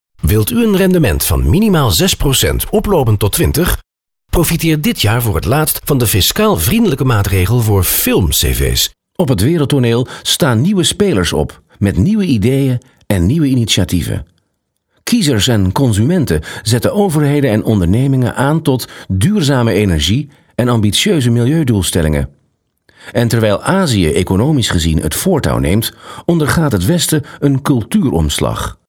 Experienced, versatile, fast.
Sprechprobe: eLearning (Muttersprache):